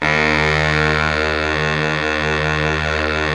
Index of /90_sSampleCDs/Roland LCDP07 Super Sax/SAX_Sax Ensemble/SAX_Sax Sect Ens
SAX 2 BARI0H.wav